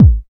70 KICK.wav